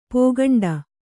♪ pōgaṇḍa